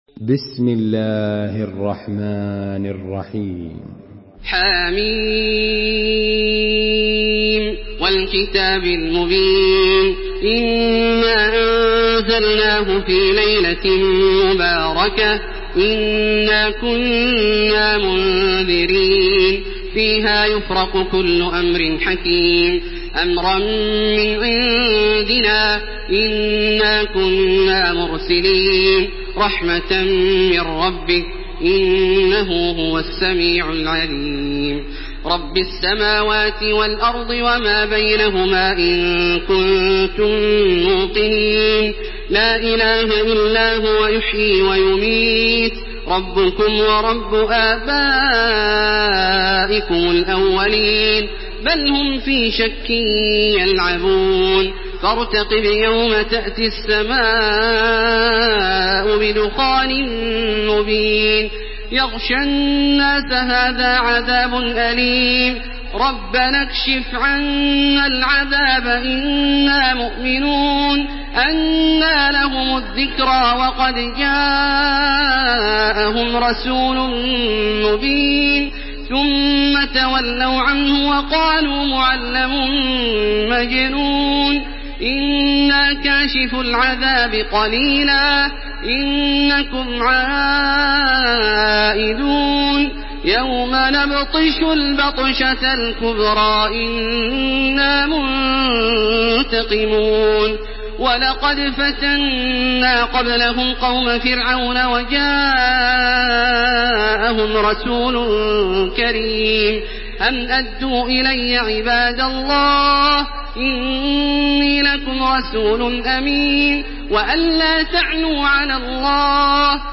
سورة الدخان MP3 بصوت تراويح الحرم المكي 1429 برواية حفص عن عاصم، استمع وحمّل التلاوة كاملة بصيغة MP3 عبر روابط مباشرة وسريعة على الجوال، مع إمكانية التحميل بجودات متعددة.
تحميل سورة الدخان بصوت تراويح الحرم المكي 1429
مرتل